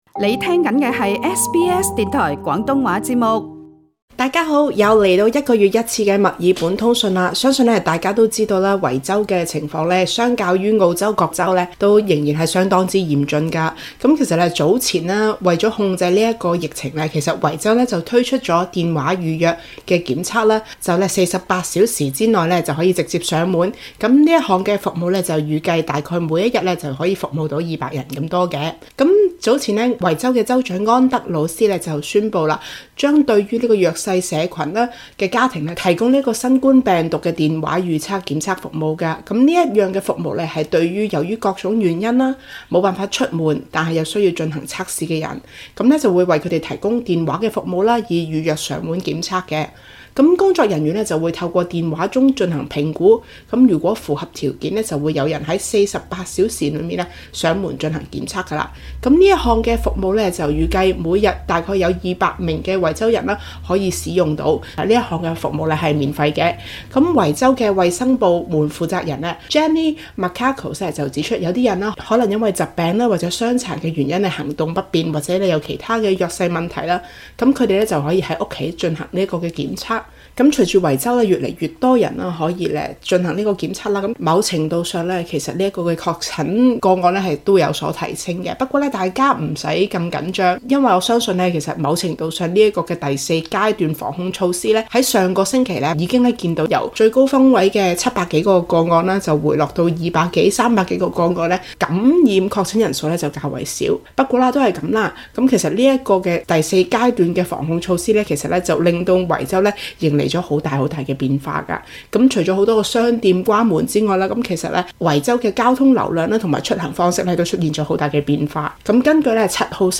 墨爾本通訊